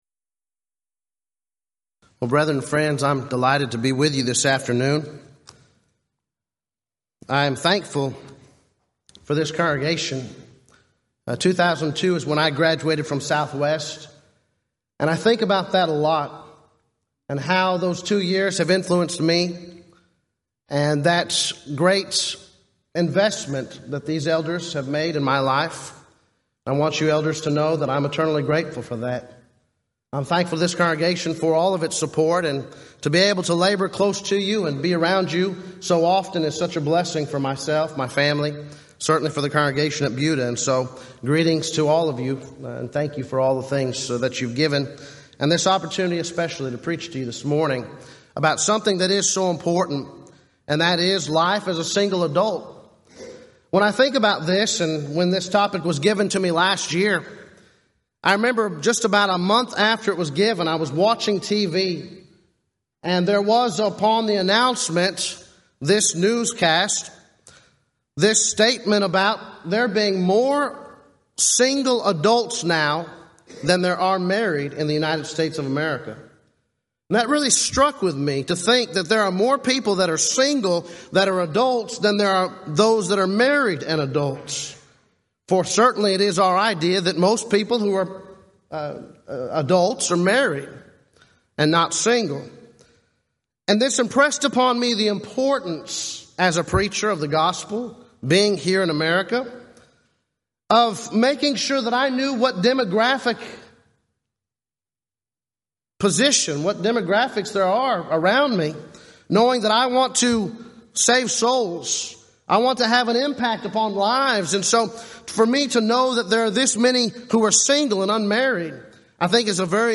Event: 34th Annual Southwest Lectures
lecture